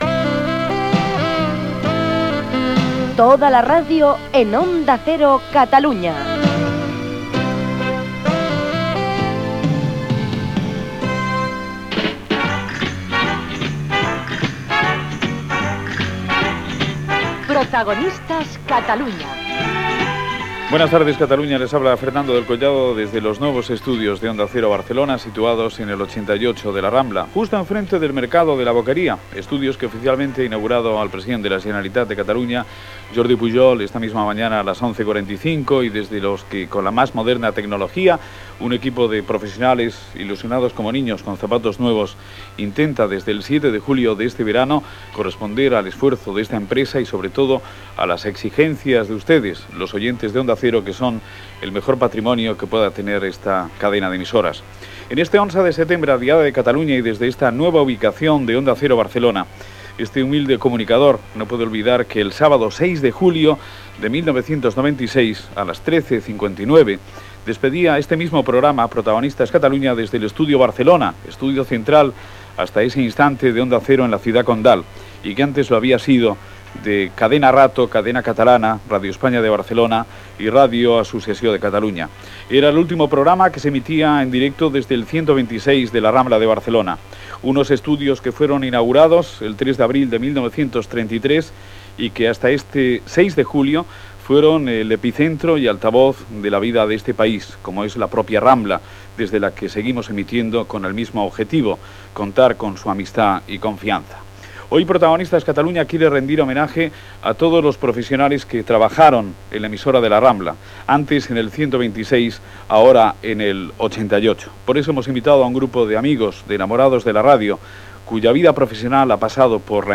Indicatiu de l'emissora i inici del programa amb connexió amb La Rambla.
Informatiu